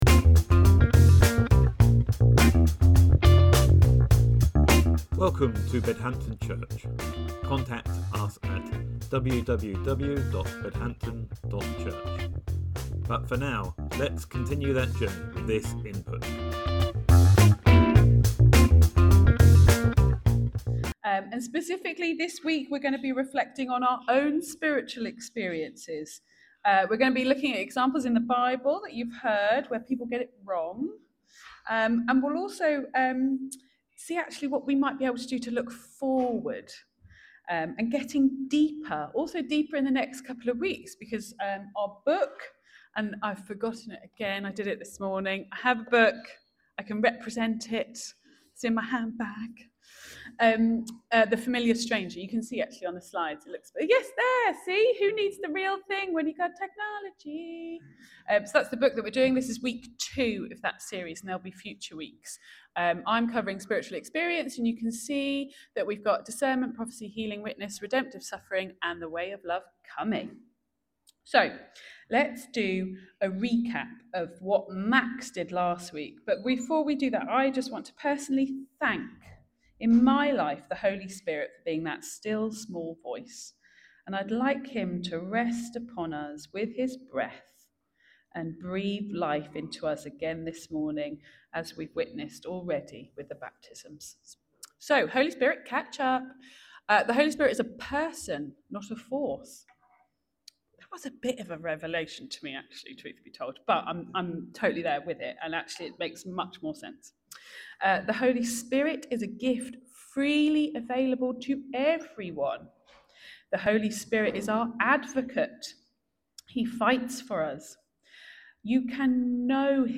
In The Familiar Stranger sermon series, we reflect upon his book as Tyler Staton reintroduces this oft-neglected Person of the Trinity, tracing the story of the Holy Spirit as it unfolds throughout the Bible, and inviting believers to close the gap between what Scripture reveals about the Holy Spirit and their lived experience.